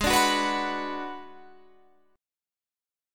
Listen to D6b5/Ab strummed